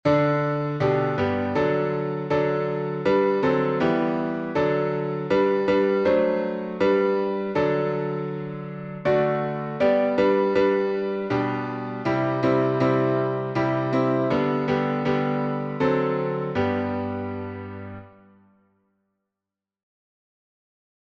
Key signature: G major (1 sharp) Time signature: 3/2 Meter: 8.6.8.6.(C.M.)